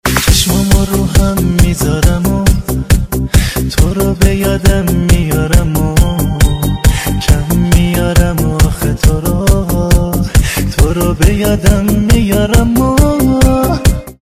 رینگتون احساسی و با کلام